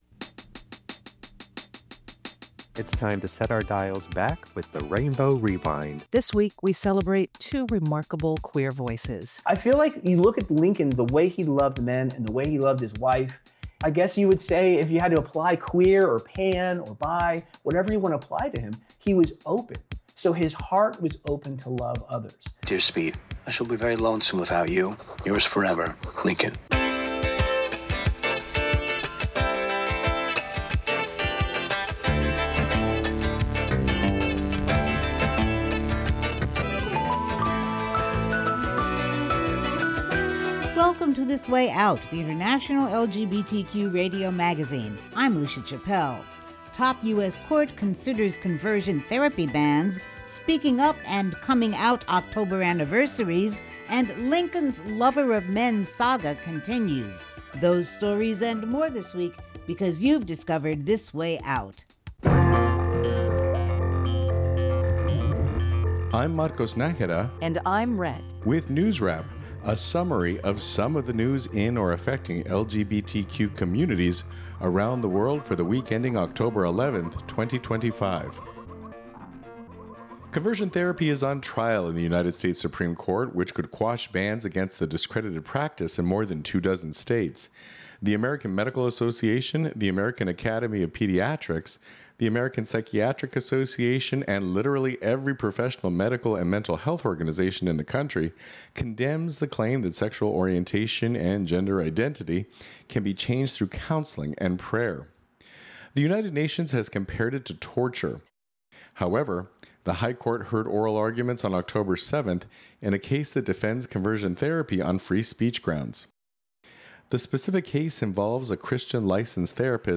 Subtitle: The International LGBTQ radio magazine wk of 10-13-25 Program Type